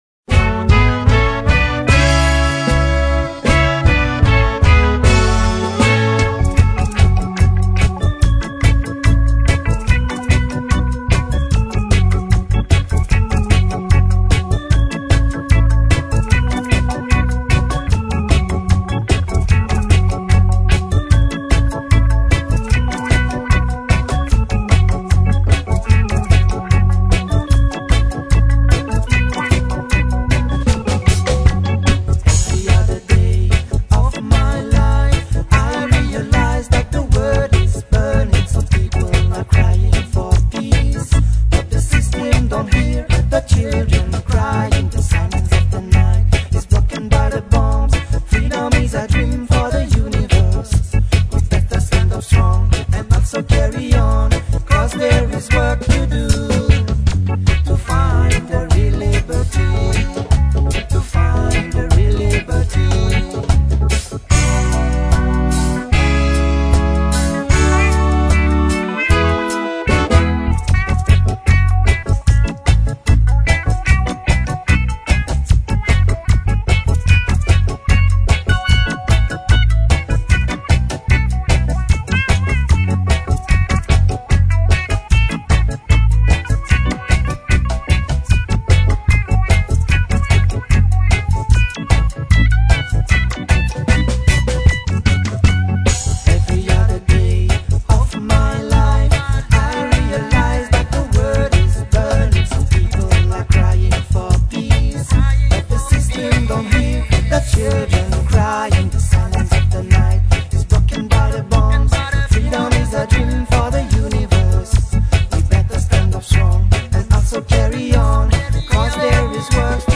Du pure Roots français !
Enfin, un groupe de reggae français qui sonne jamaïcain